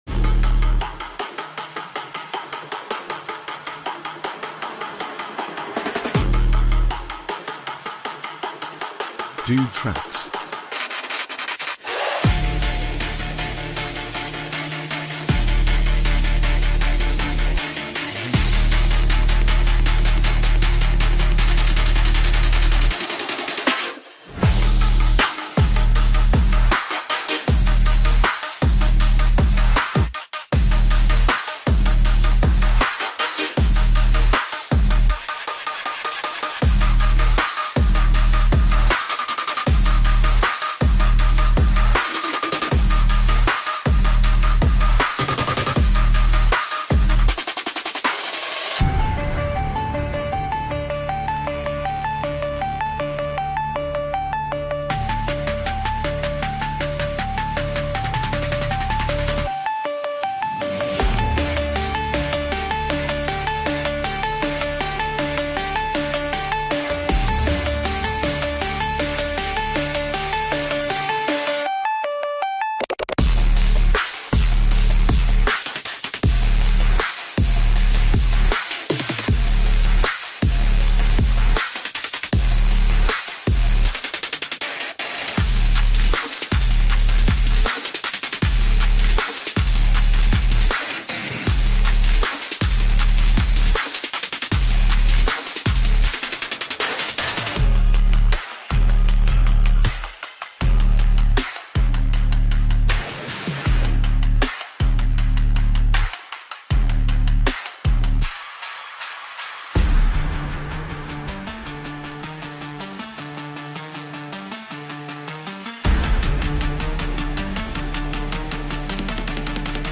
Royalty-Free Hip Hop Beat